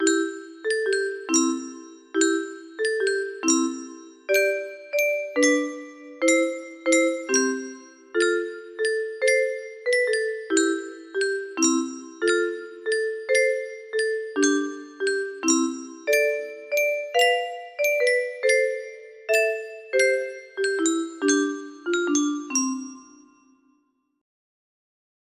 Silent Night 106 music box melody
Simple Silent night - 106 beats